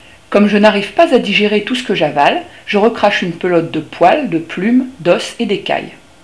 Clique sur la note pour écouter mon chant.